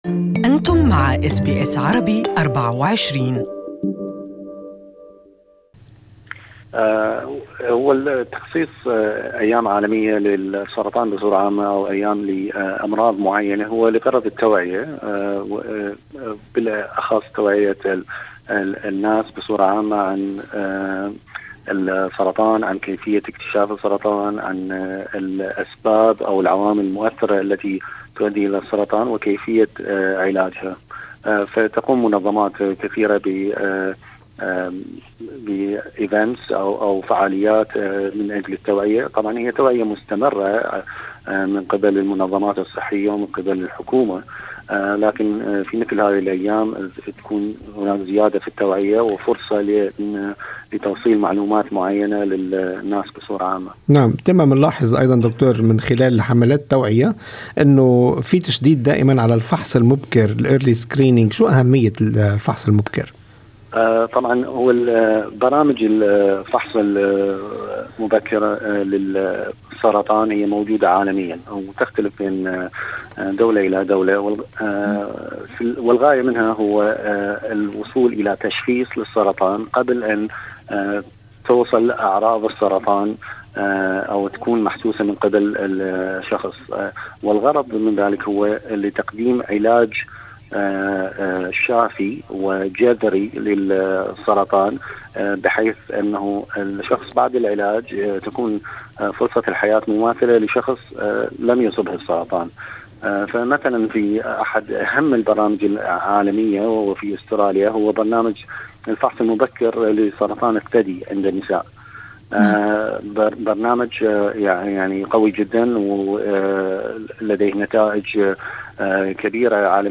ويأتي هذا اللقاء على خلفية اليوم العالمي للسرطان الذي صادف أمس الاثنين في الرابع من شهر شباط فبراير الجاري.